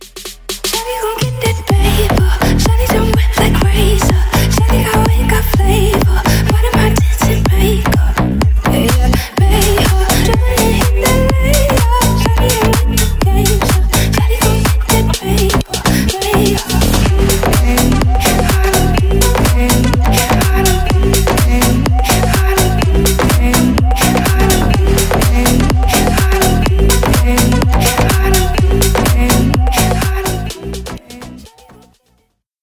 Ремикс # Поп Музыка
громкие